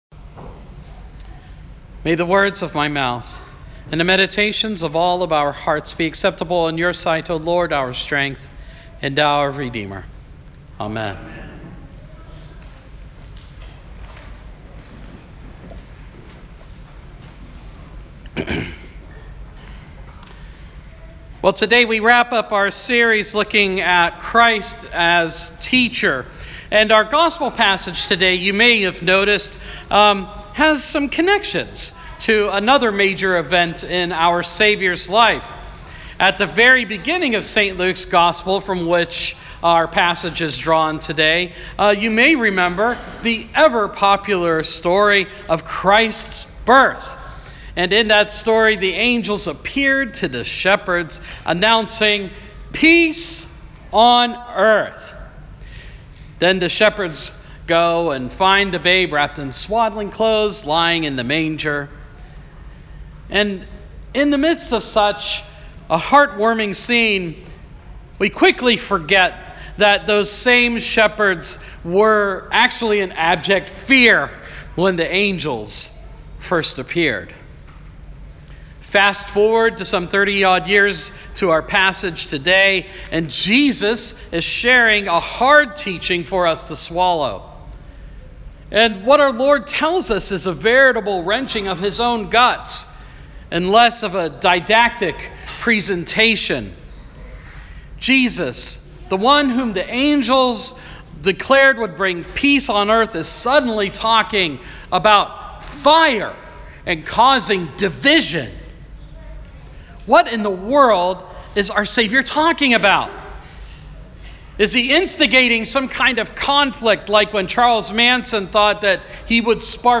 Sermon – Proper 15 – 2019